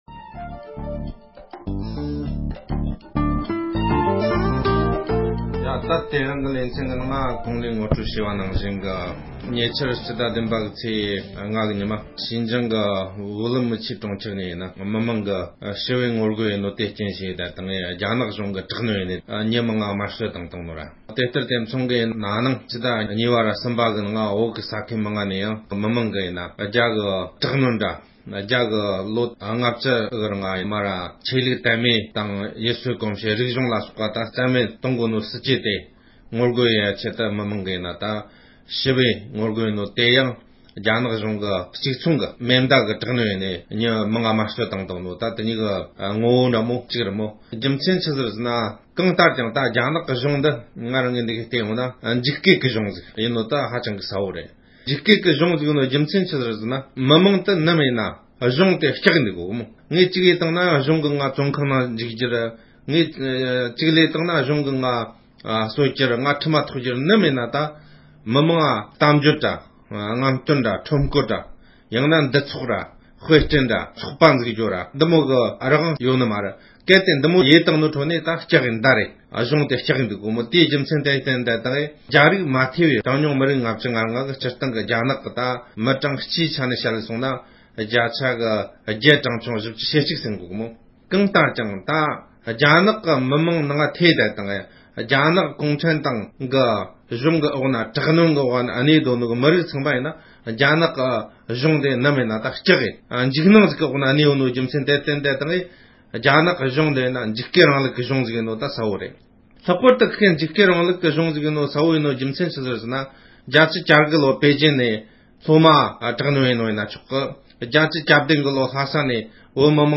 རྒྱ་གཞུང་གིས་ཡུ་གུར་མི་རིཊ་ཐོག་དྲག་གནོན་བྱས་པ་དེས་རྒྱལ་ཁབ་གཅིག་འགྱུར་གྱི་རྩ་དོན་གཏོར་བཤིག་གཏོང་བཞིན་ཡོད་པའི་སྐོར་དཔྱད་གཏམ།